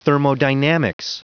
Prononciation du mot thermodynamics en anglais (fichier audio)